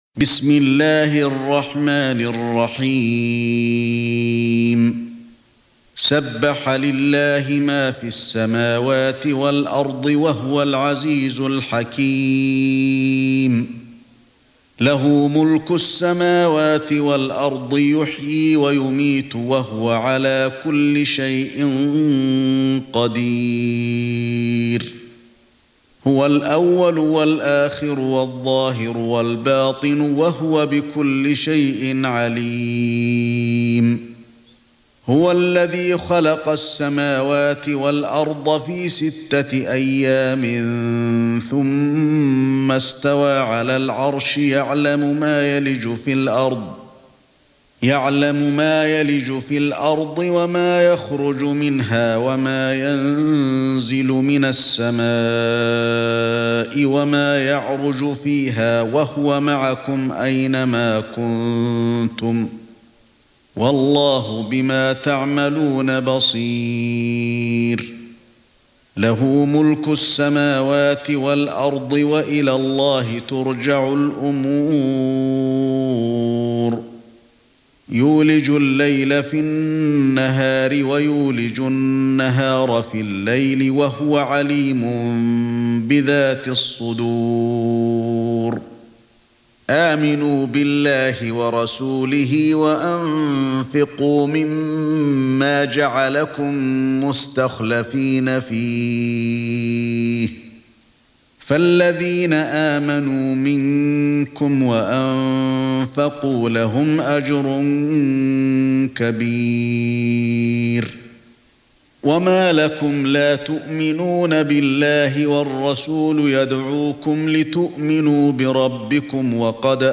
سورة الحديد ( برواية قالون ) > مصحف الشيخ علي الحذيفي ( رواية قالون ) > المصحف - تلاوات الحرمين